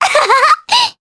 Cleo-Vox_Happy3_jp.wav